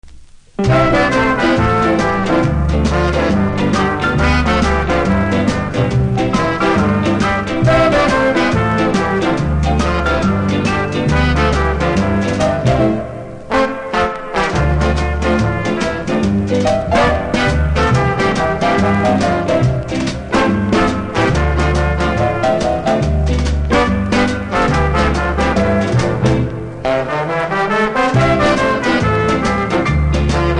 キズは多めですが音は良いので試聴で確認下さい。